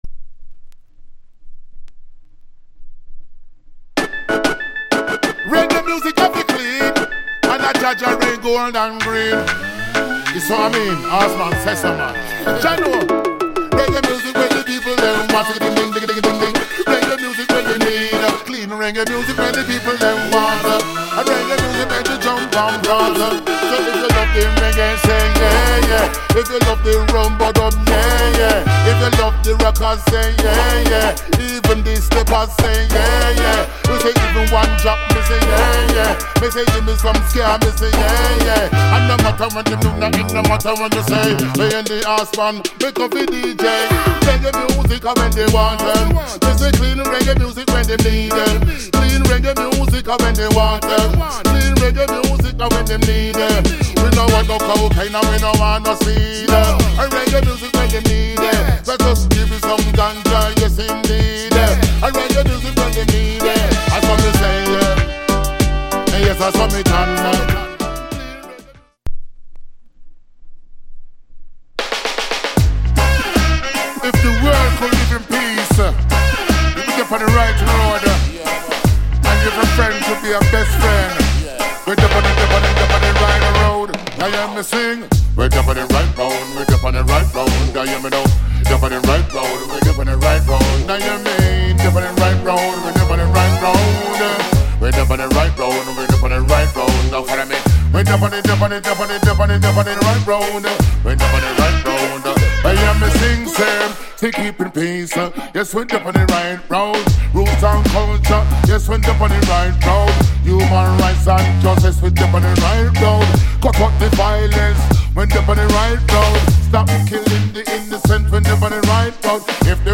Genre / Male DJ